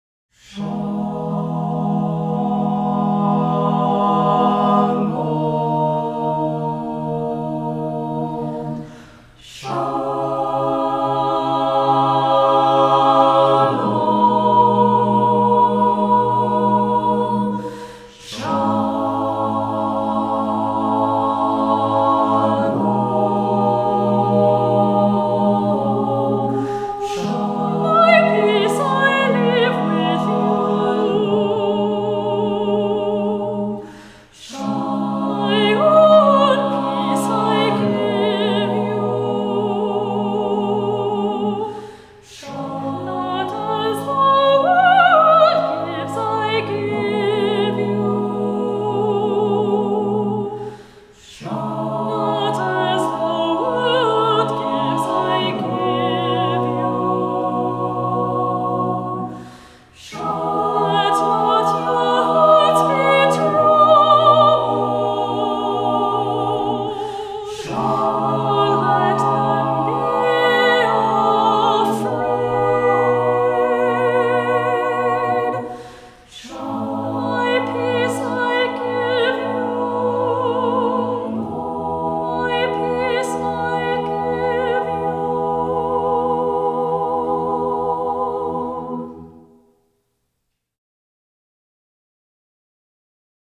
Voicing: Soprano Soloist,Tenor Soloist,SATB